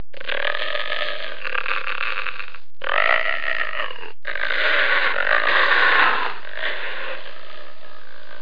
links-monstergrowl2.mp3